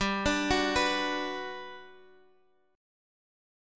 Bm/G chord
B-Minor-G-x,x,5,7,7,7-8.m4a